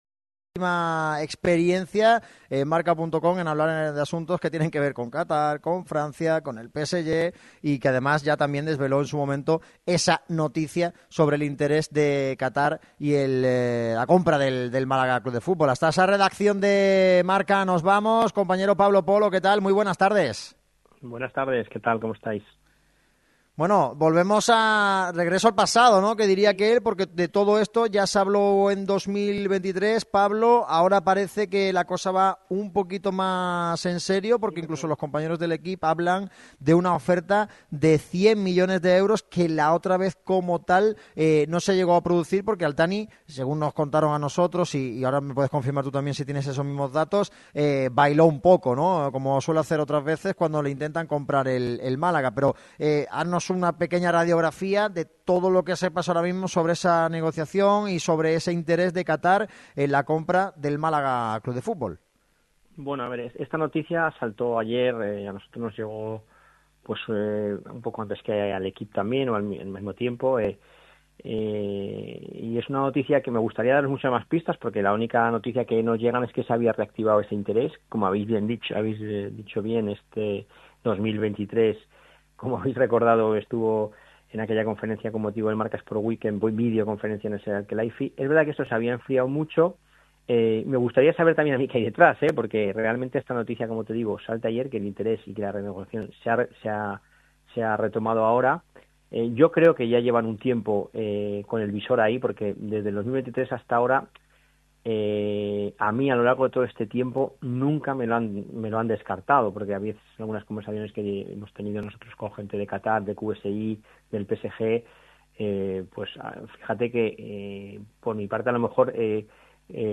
se ha pasado por el micrófono rojo de Radio MARCA Málaga para ampliar los detalles de la posible operación. Tal y como avanzó también L’Equipe, Qatar Sports Investments tendría la intención de que la compra del Málaga se cierre rápido.